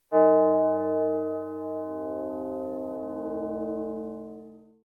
Bell1.ogg